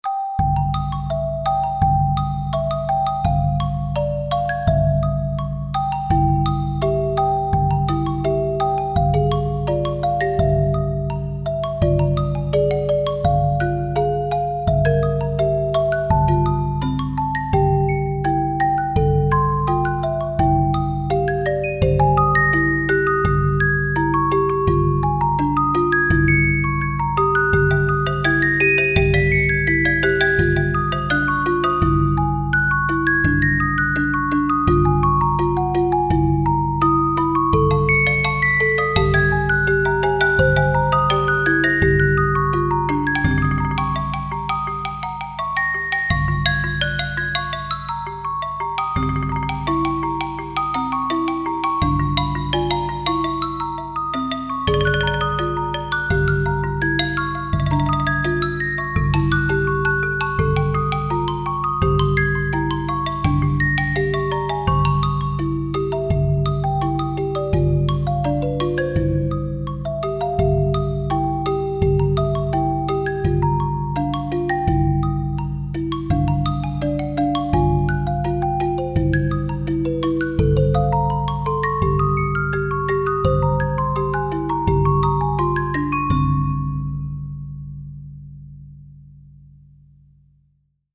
Ihr Kinderlein kommet (Text: Chr. v. Schmid, 1768 – 1854, Melodie: Joh. A. P. Schulz, 1794) Hier geht es etwas zeitgenössischer zu, gewisse Parallelen zu den musikalischen Auffassungen von Charles Ives' Vater mögen die Experten zum Schmunzeln bringen. Die Idee ist einfach: die Melodie steht in C−Dur, das sind die weißen Tasten des Klaviers, die Begleitstimme verwendet die Töne der schwarzen Tasten. Zusammen sind das alle 12 Töne: An der Krippe sind alle gleichermaßen willkommen.